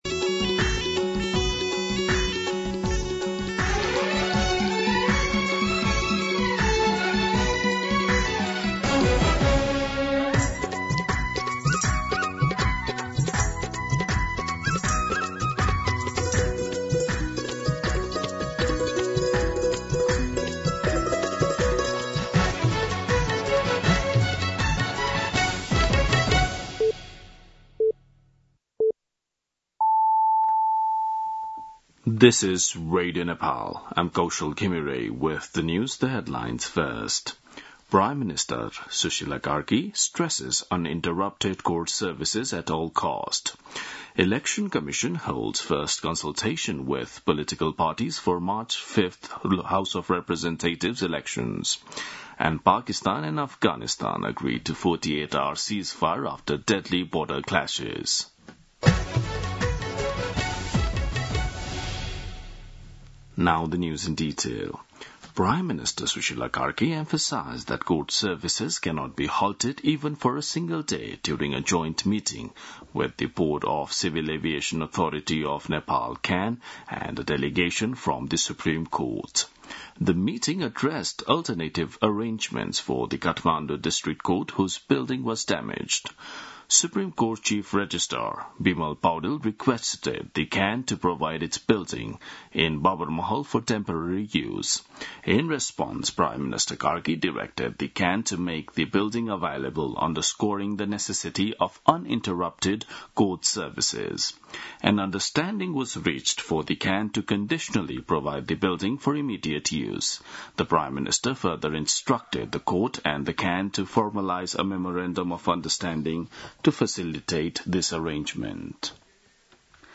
दिउँसो २ बजेको अङ्ग्रेजी समाचार : ३० असोज , २०८२
2-pm-English-News-4.mp3